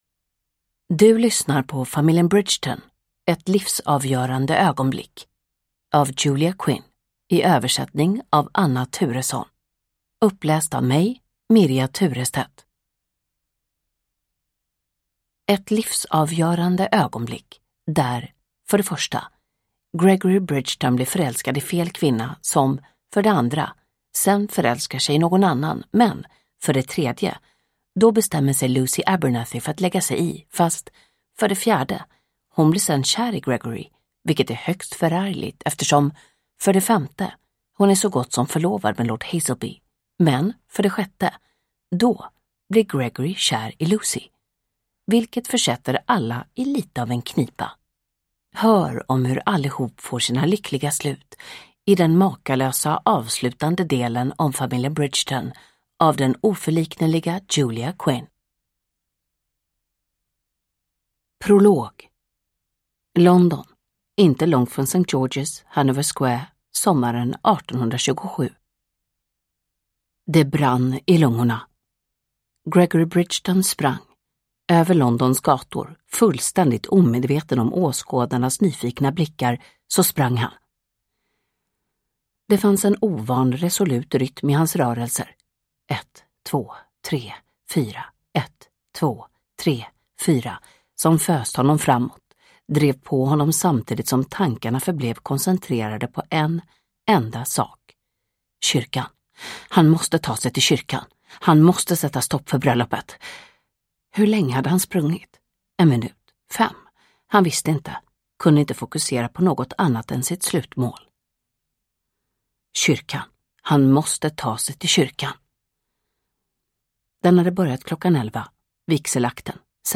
Ett livsavgörande ögonblick – Ljudbok – Laddas ner